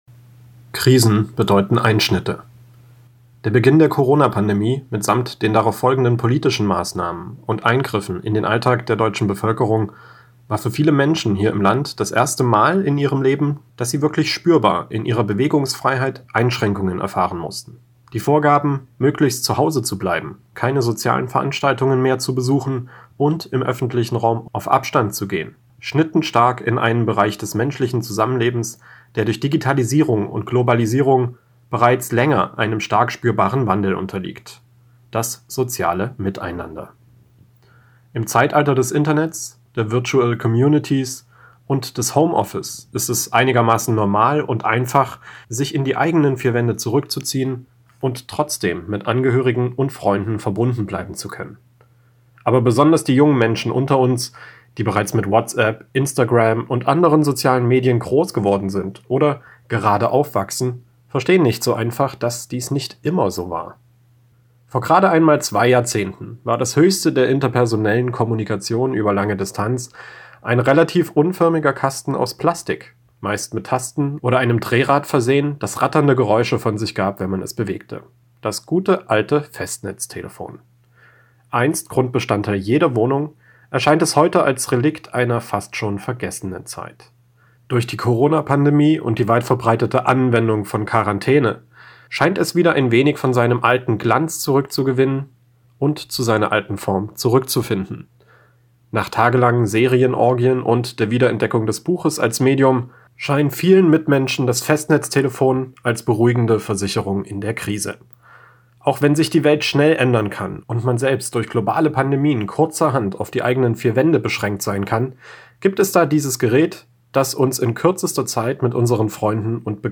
Sendung: Mittendrin Redaktion Kommentar